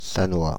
Sannois (French pronunciation: [sanwa]
Fr-Paris--Sannois.ogg.mp3